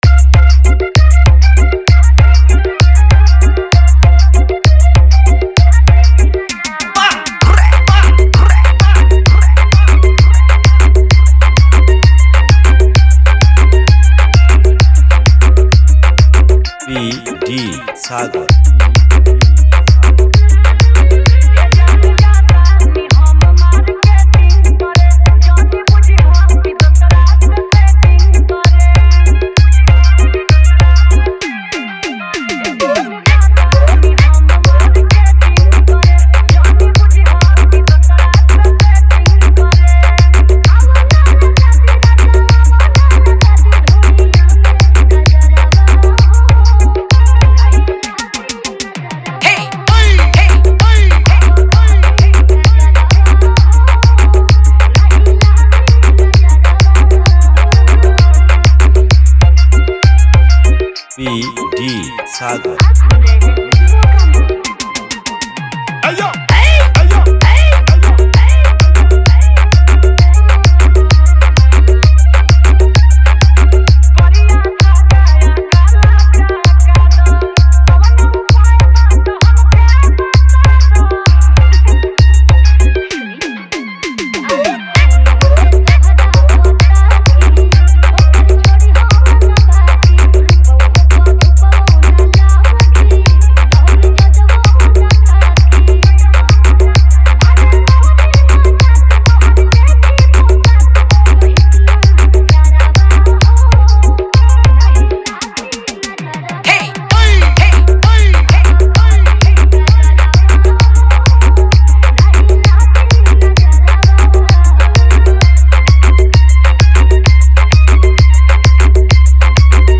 Bhojpuri Humbing Dance Mix